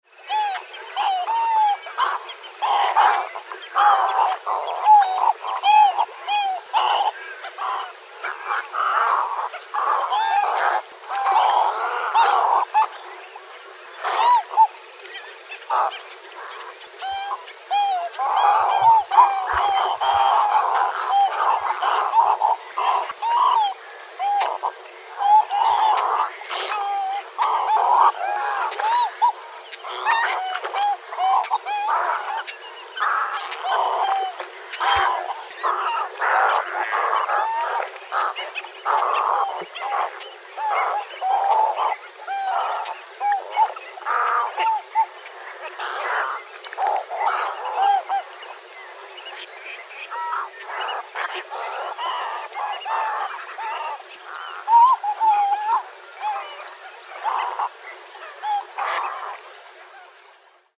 Location: Saladillo, Buenos Aires.
snowy-egret-egretta-thula1.mp3